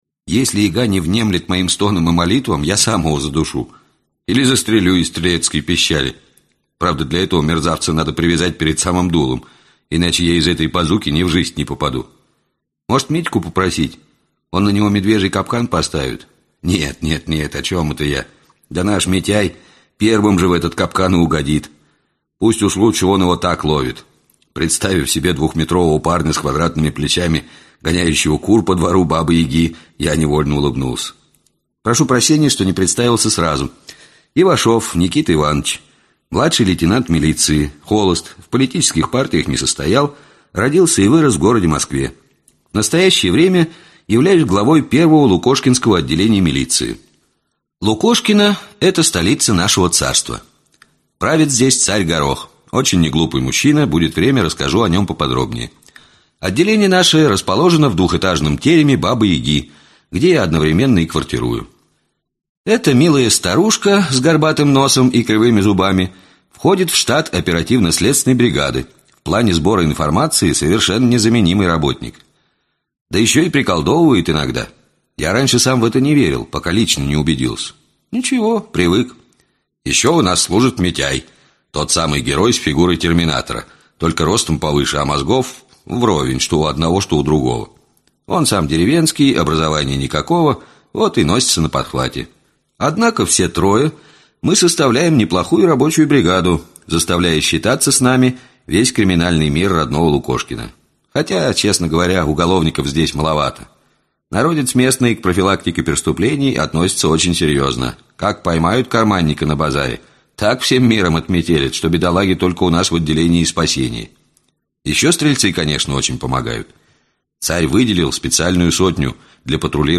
Аудиокнига Заговор Черной Мессы | Библиотека аудиокниг